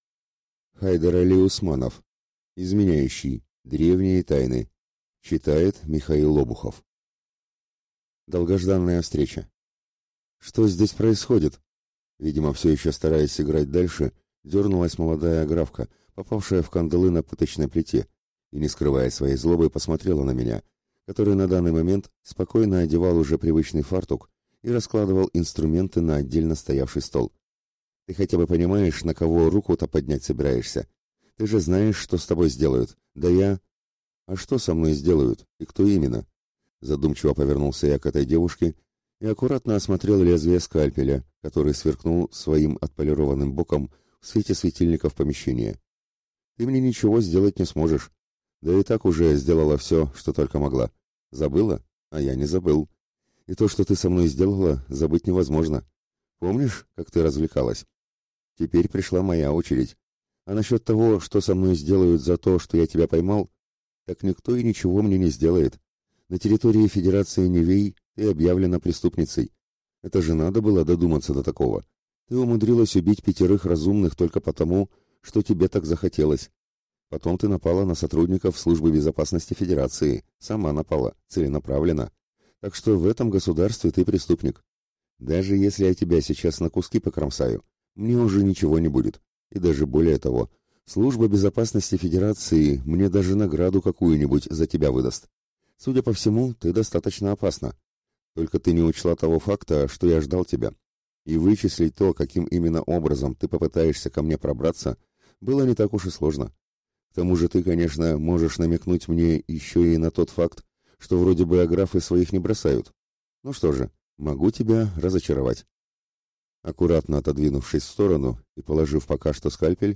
Аудиокнига Изменяющий. Древние тайны | Библиотека аудиокниг